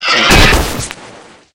die_3.ogg